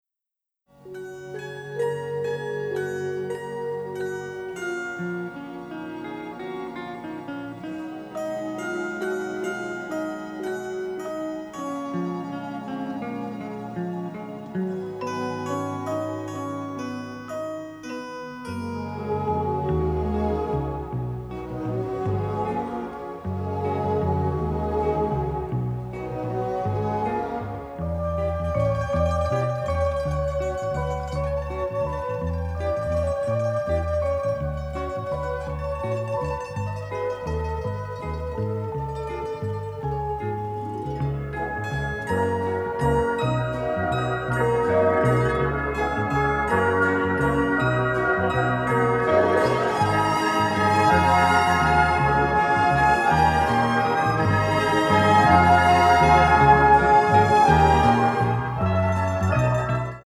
soundtrack album
builds upon classic Italian songs
original stereo session elements